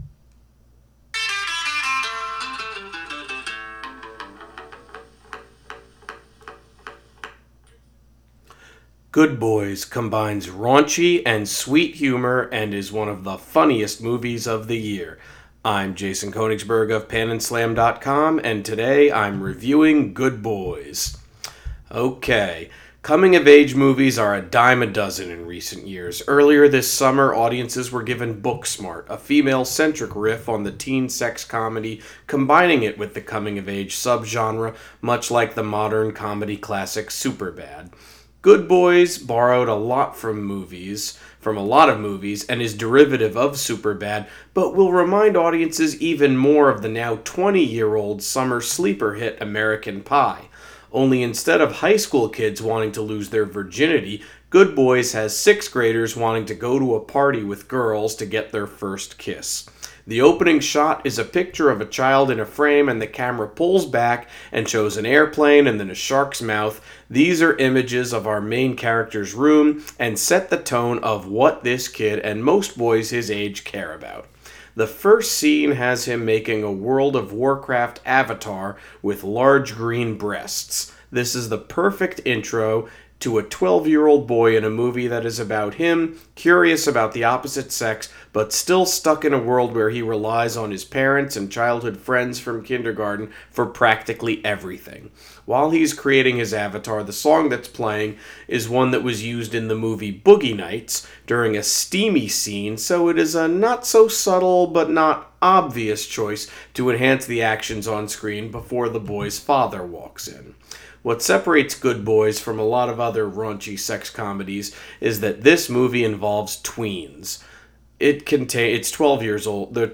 Movie Review: Good Boys